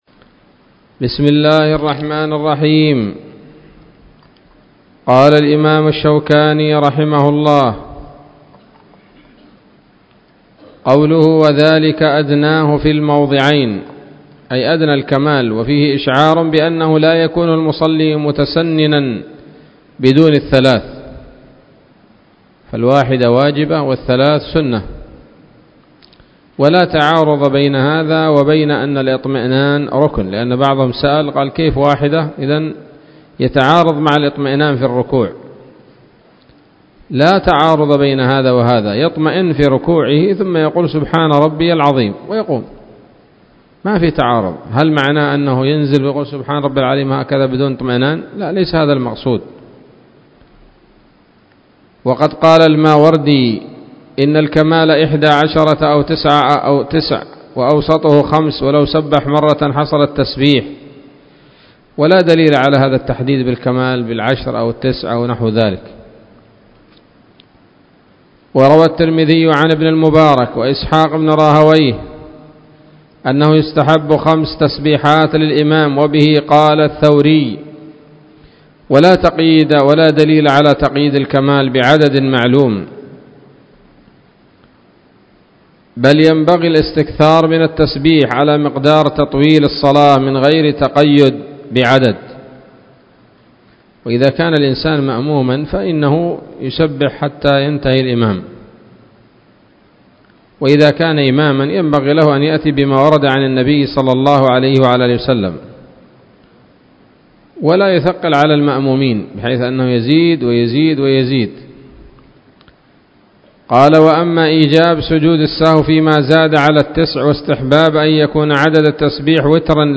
الدرس السابع والخمسون من أبواب صفة الصلاة من نيل الأوطار